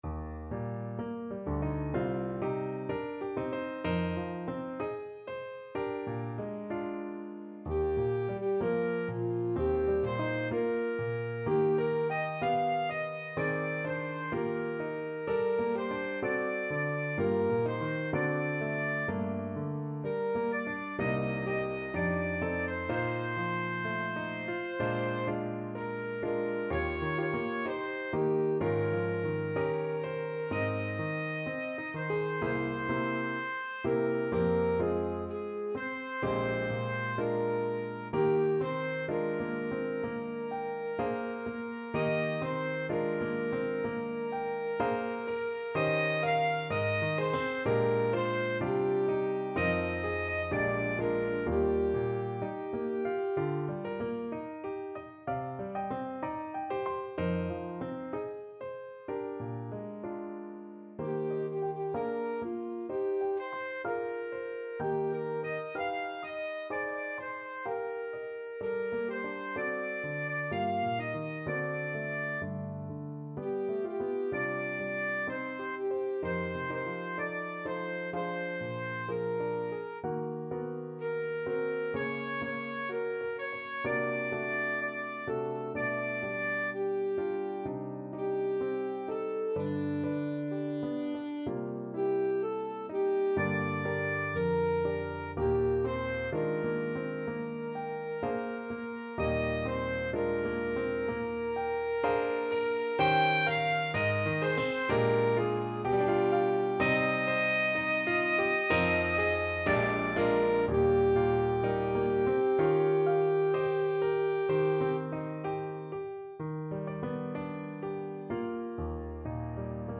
Alto Saxophone
4/4 (View more 4/4 Music)
D5-G6
Classical (View more Classical Saxophone Music)